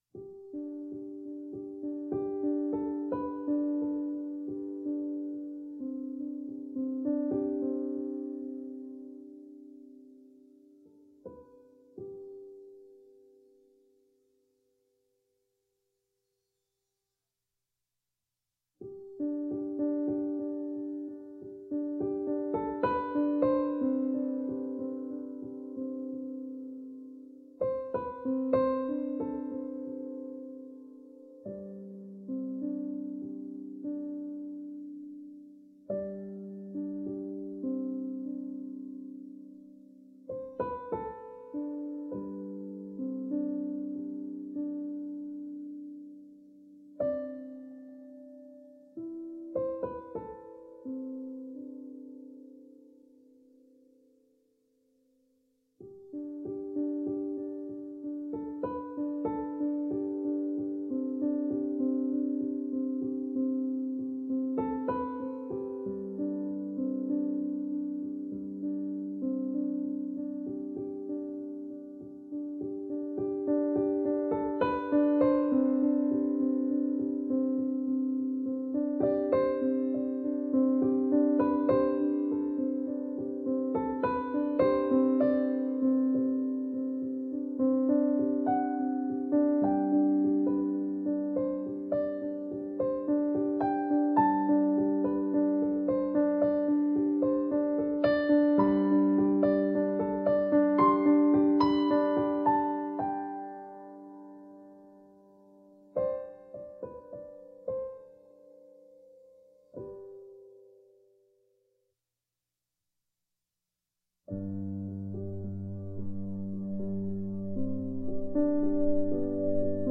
Minimalist piano with slashes of ambient electronics.